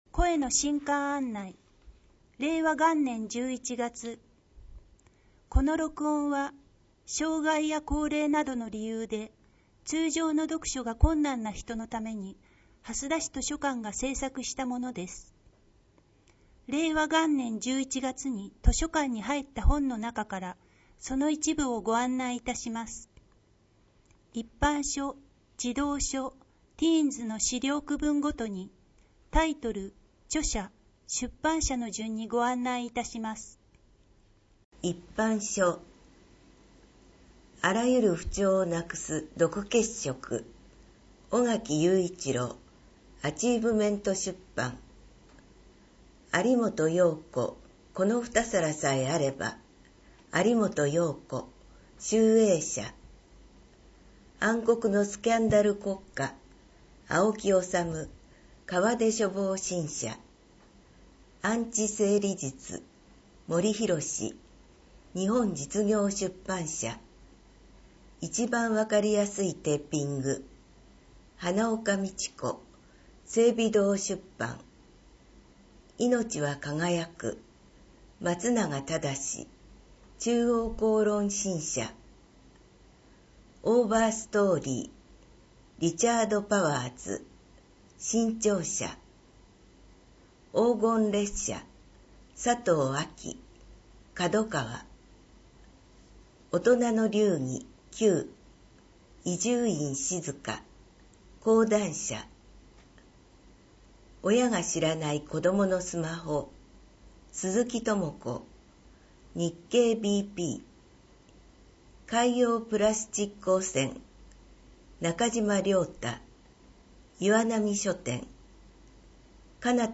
声の新刊案内 図書館に新しく入った本を音声で紹介します 2017年5月の本 2018年7月の本 2018年9月の本 2019年2月の本 2019年6月の本 2019年8月の本 2019年9月の本 2019年10月の本 2019年11月の本